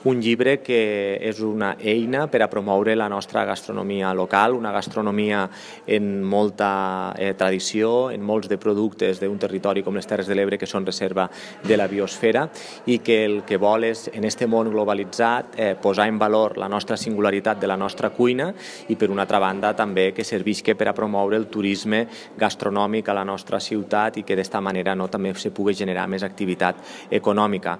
Reivindicar la identitat gastronòmica, els productes i les receptes culinàries de Tortosa com a elements per captar visitants al municipi, són els objectius del Llibre Blanc del Turisme Gastronòmic que ha presentat  l’Ajuntament.  Jordi Jordan, és alcalde de Tortosa…